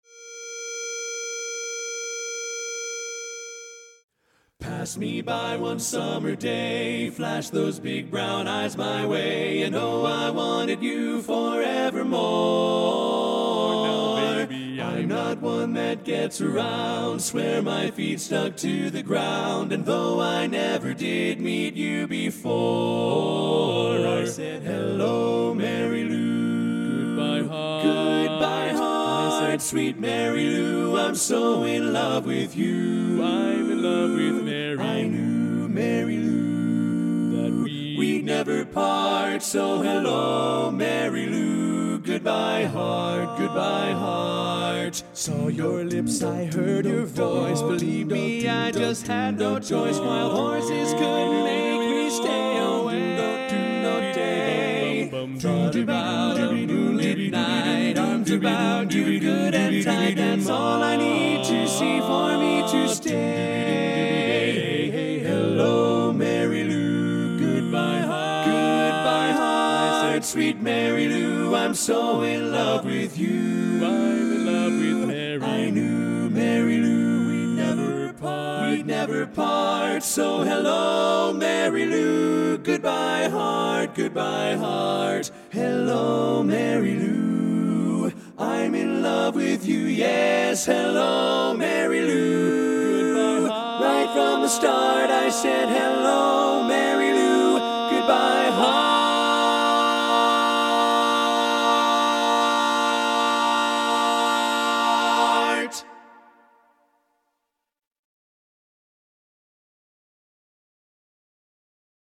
Kanawha Kordsmen (chorus)
Up-tempo
B♭ Major
Bari